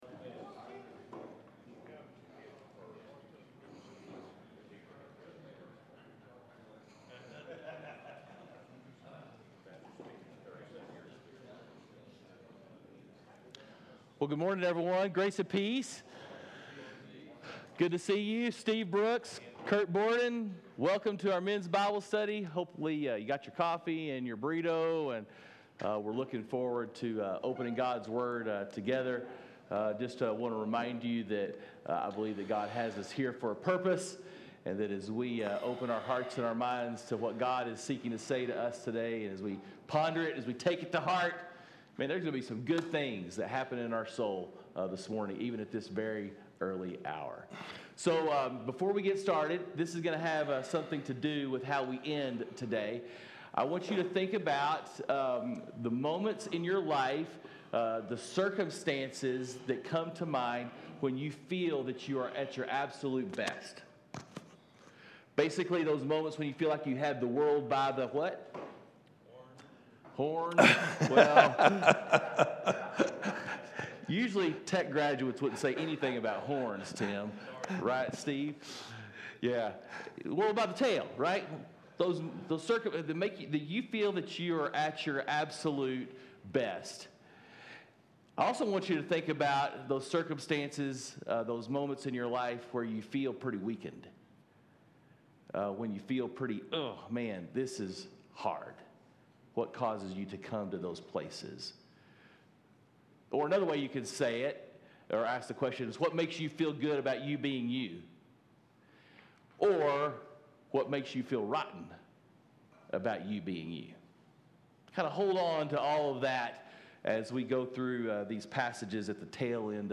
Men’s Breakfast Bible Study 8/18/20